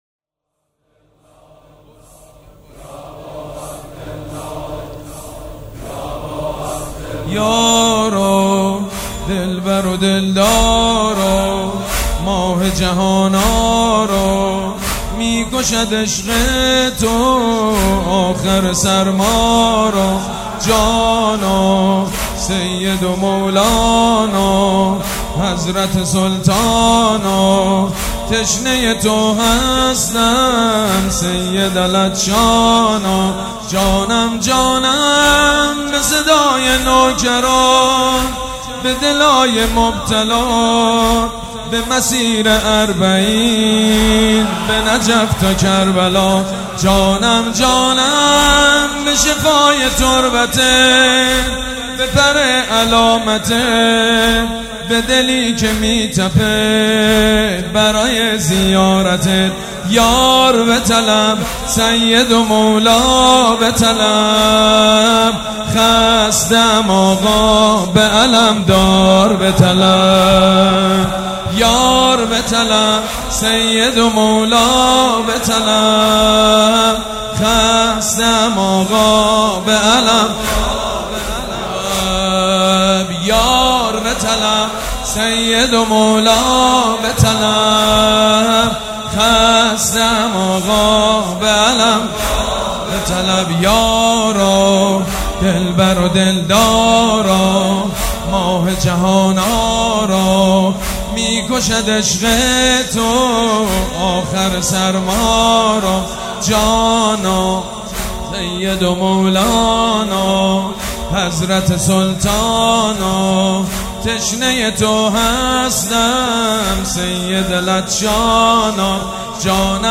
مداحی و نوحه
دانلود banifatemeh-shab2moharram139703.mp3 MP3 ۶ MB